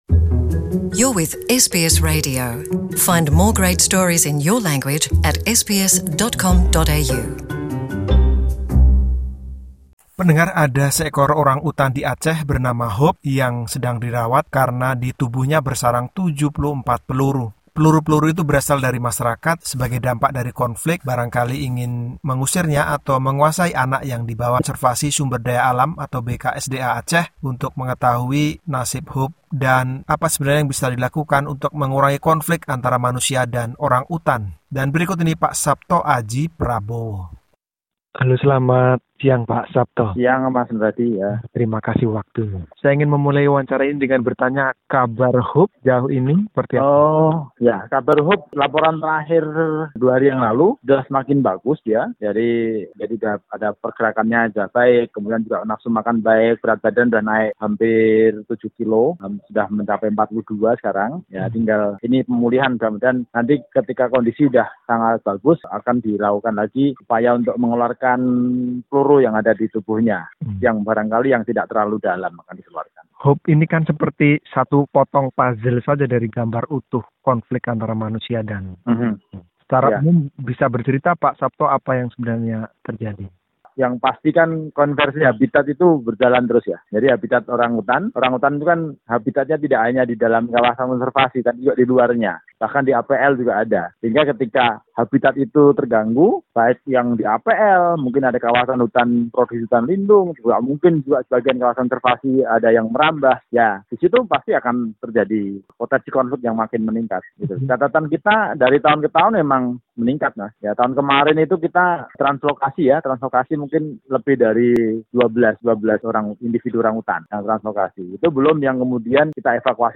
Kepala Balai Konservasi Sumber Daya Alam (BKSDA) Banda Aceh, Sapto Aji Prabowo menuturkan konflik antara orangutan dan penduduk di Aceh.